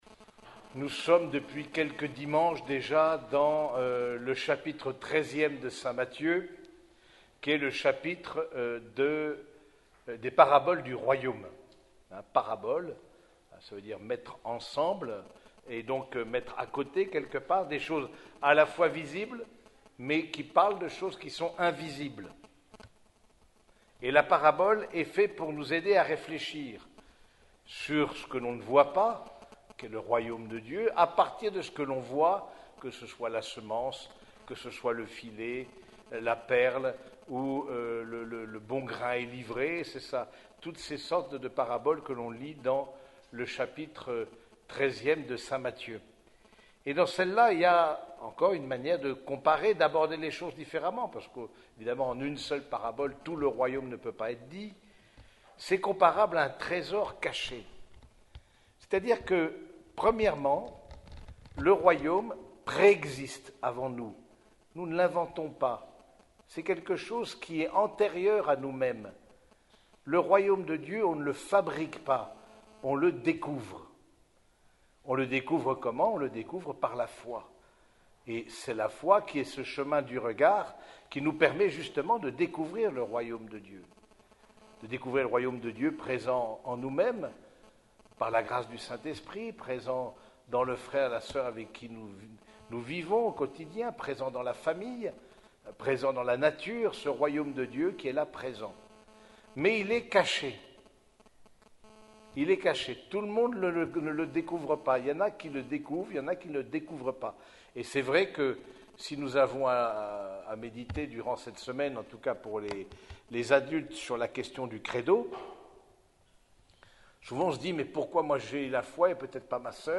Homélie du 17e dimanche du Temps Ordinaire